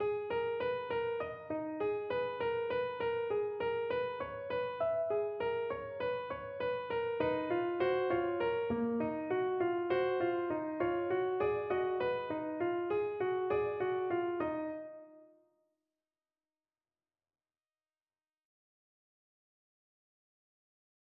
예를 들어, 바흐의 ''잘 템퍼드 클라비어'' 제2권에 있는 푸가 G#단조의 서주 주제에 함축된 화성은 두 번째 성부가 추가될 때 미묘한 방식으로 새롭게 들린다.[10]
바흐 G#단조 푸가, 잘 템퍼드 클라비어 제2권